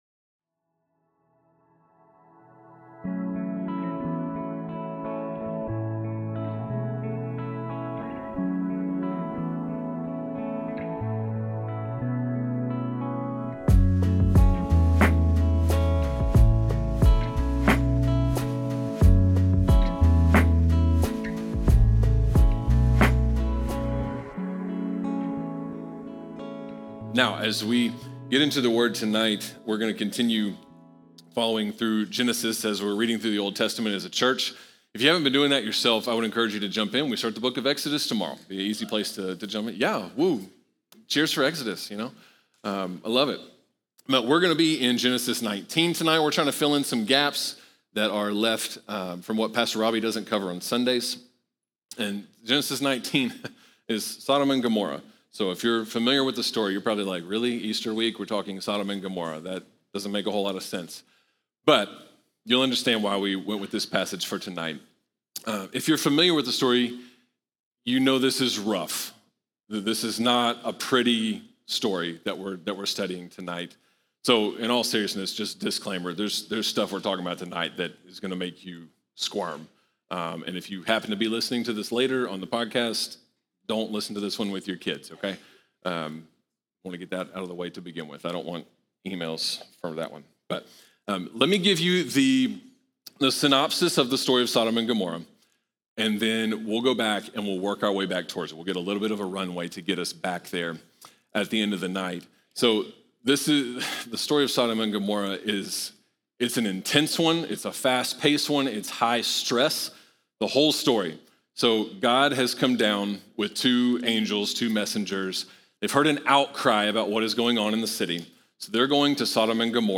Recorded live at Long Hollow Church on March 31, 2026.